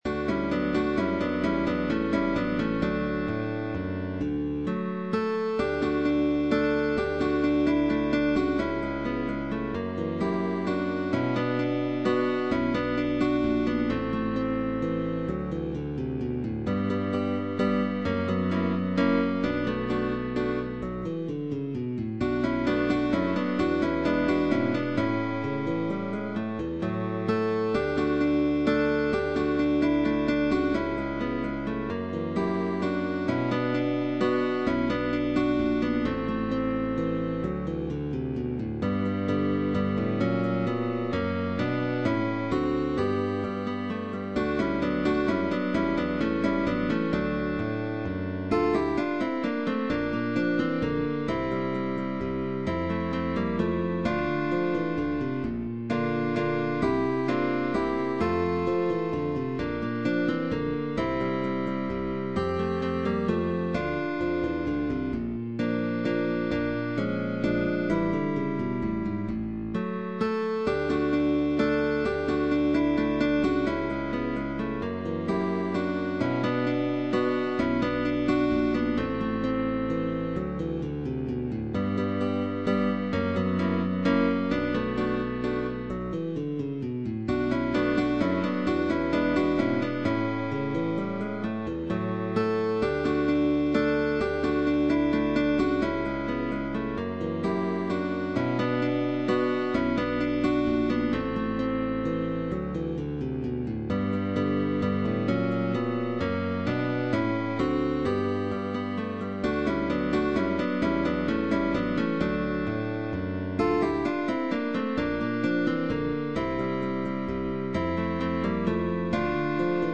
TRIO DE GUITARRAS